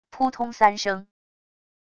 噗通三声wav音频